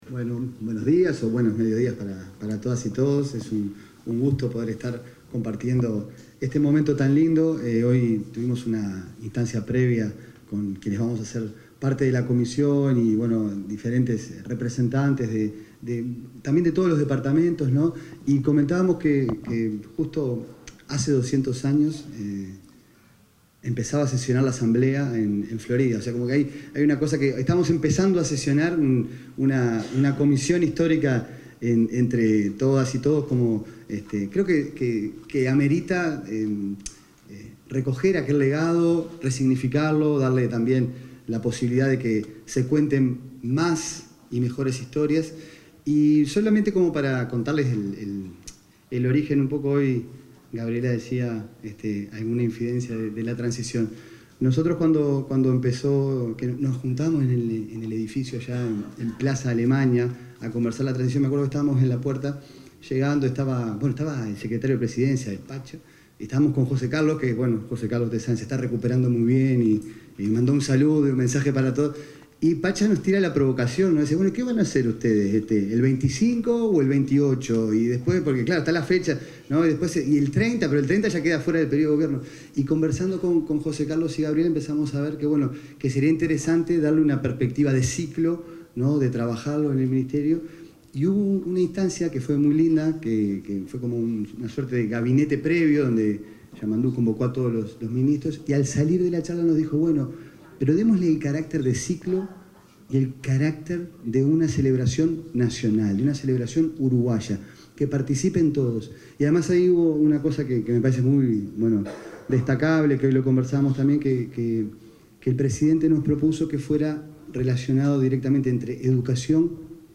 Palabras del director nacional de Educación, Gabriel Quirici
Durante el lanzamiento de las celebraciones de los 200 años del proceso de creación de la República Oriental del Uruguay, disertó el director nacional